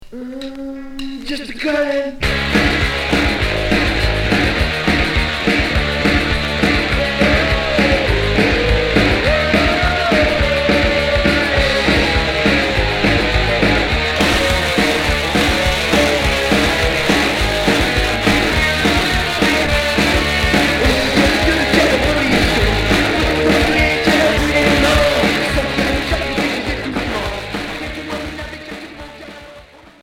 Punk rock Troisième 45t retour à l'accueil